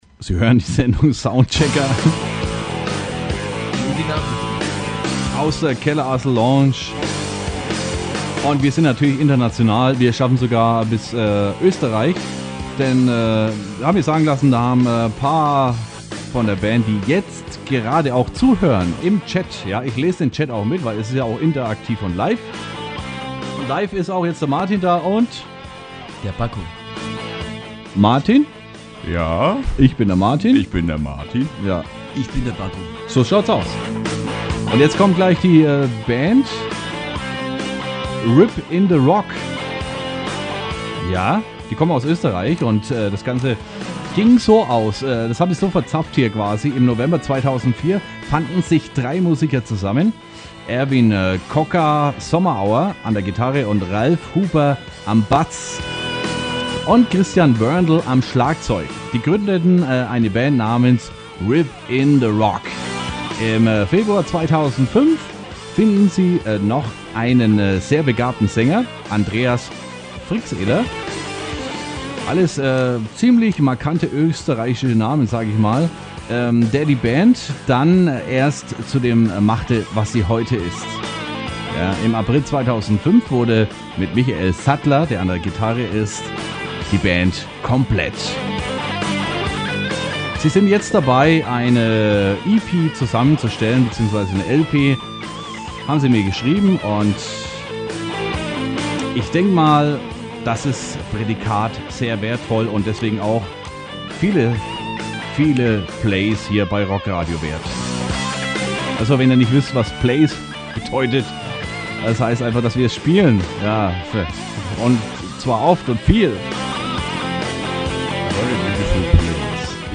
Mitschnitt-Radioauftritt